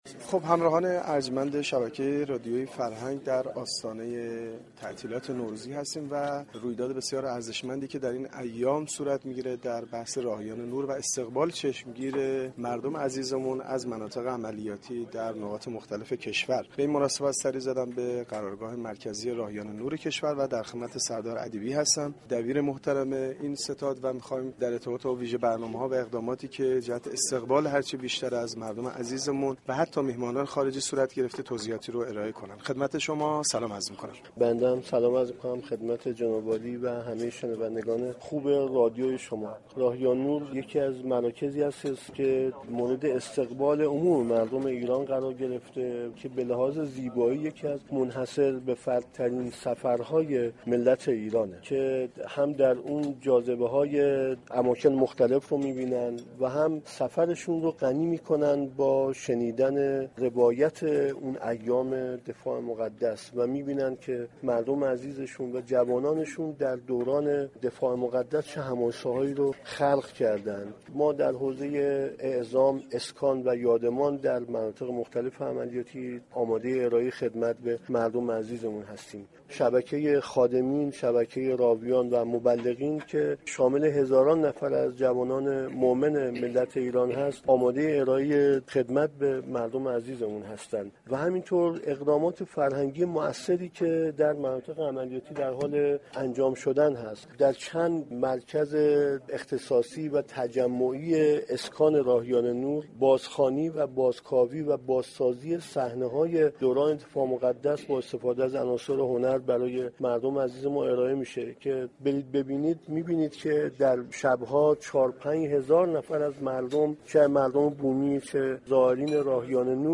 در آستانه ی نوروز ، اردوهای راهیان نور هم چون سالهای گذشته برگزار می شود. سردار نادر ادیبی دبیر محترم ستاد راهیان نور در گفتگوی اختصاصی با گزارشگر رادیو فرهنگ درباره ی ویژه برنامه ها و اقدامات انجام شده برای استقبال از هم وطنانمان و مهمانان خارجی در مناطق عملیاتی گفت : اردوهای راهیان نور یكی ازسفرهایی است كه مورد استقبال عموم مردم قرار گرفته و منحصر به فرد محسوب می شود زیرا كه مردم علاوه بر بازدید از اماكن مختلف منطقه ، روایت سالهای دفاع مقدس را نیز می شنوند .